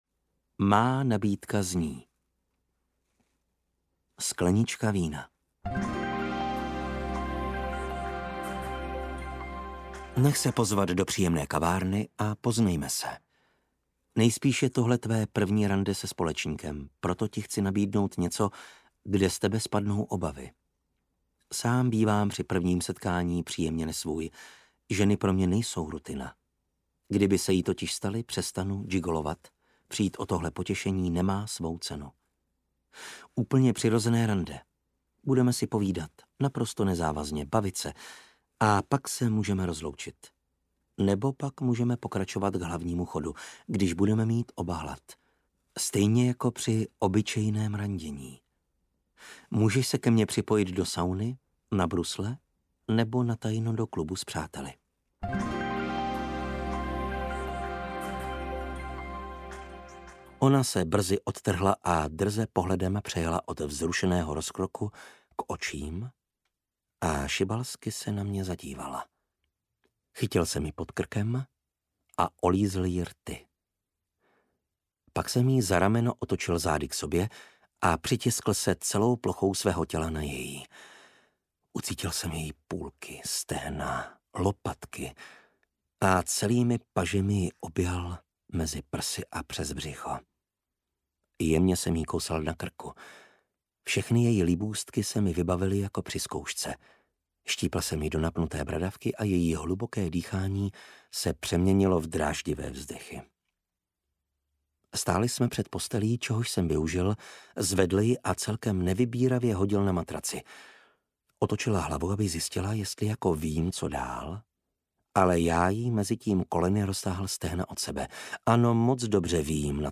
Ukázka z knihy
gigolo-zpoved-luxusniho-spolecnika-audiokniha